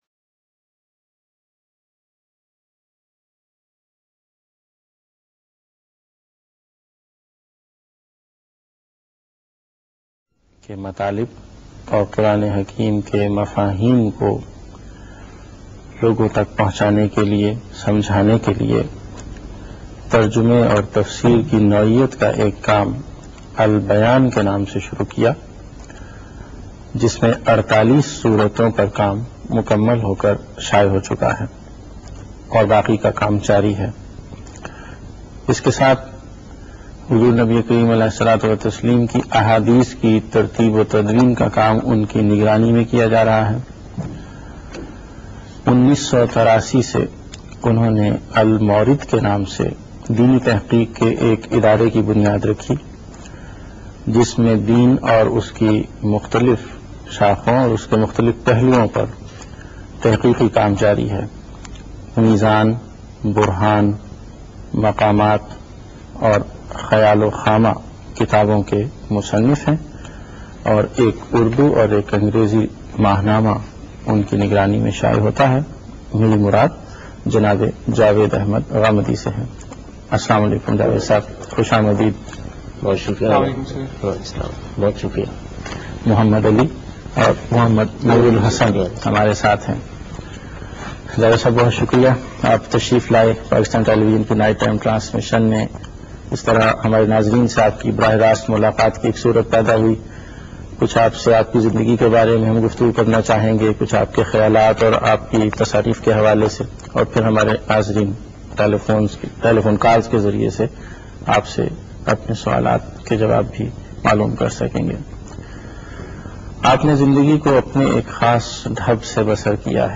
Detailed Personal Interview of Javed Ahmed Ghamidi on PTV Late Night Transmission.